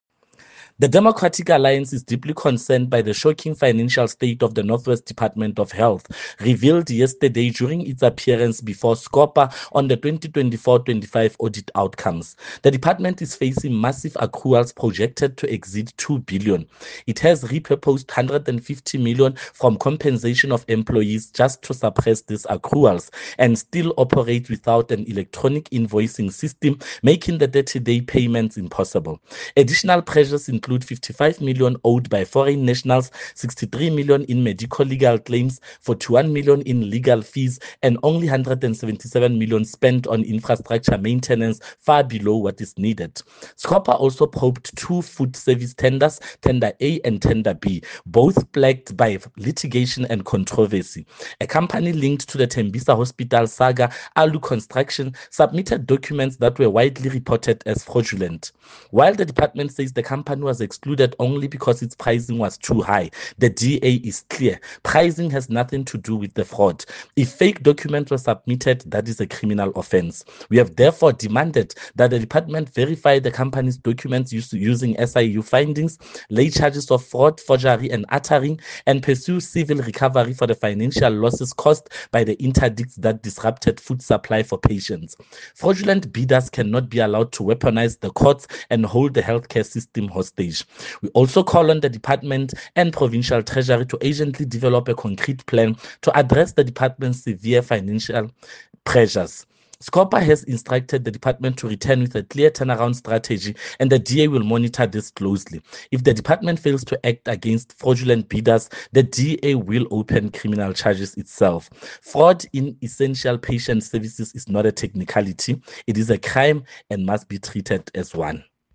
Note to Broadcasters: Please find the attached soundbite in